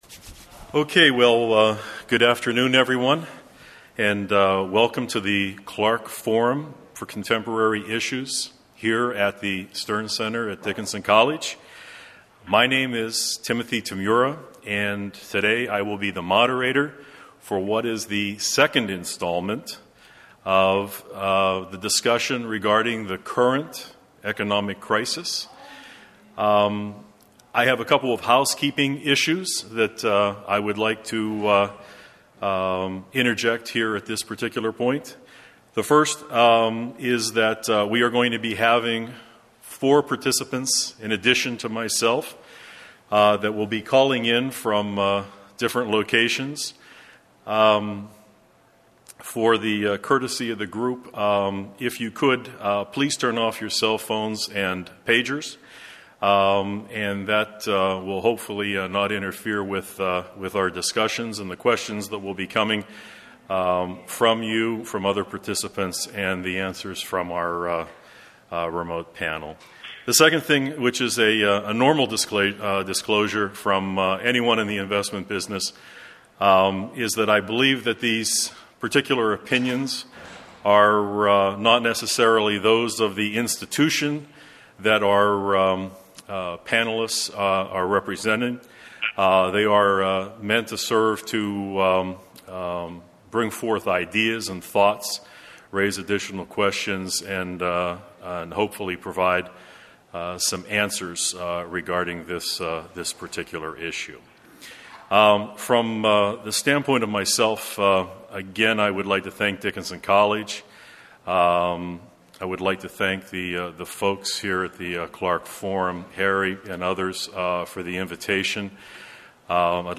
Teleconference presentations featuring financial experts associated with Dickinson College, held on 10/22/08.